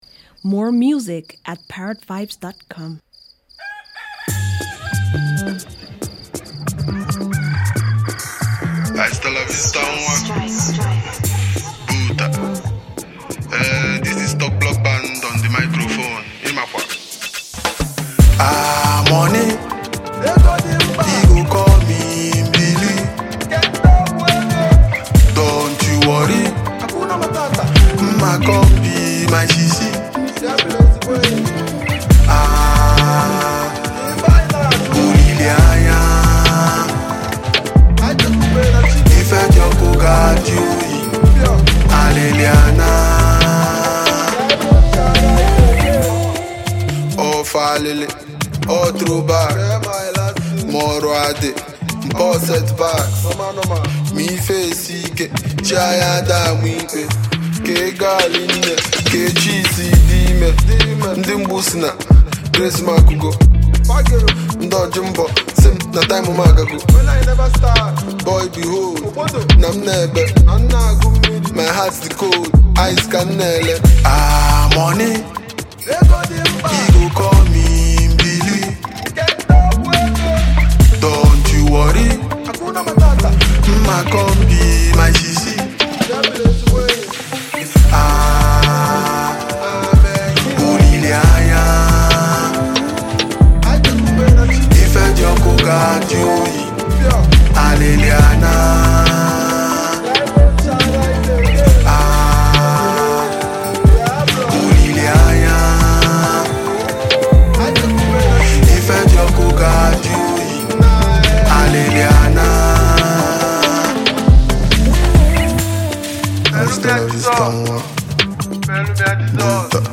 Sensational Nigerian native singer, rapper, and performer